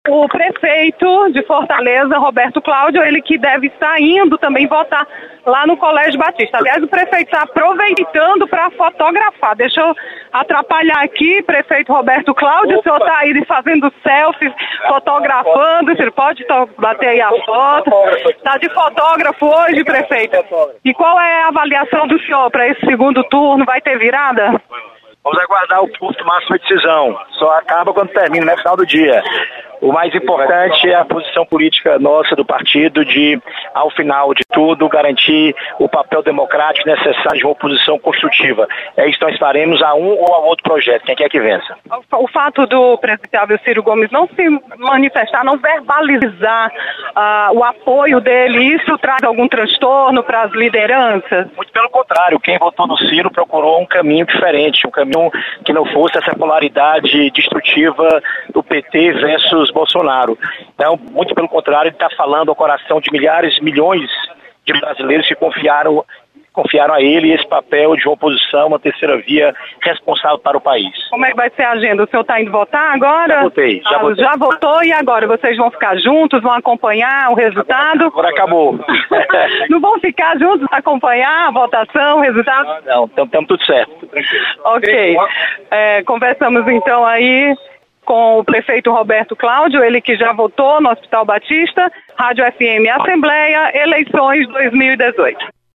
Prefeito Roberto Cláudio fala sobre segundo turno das eleições 2018.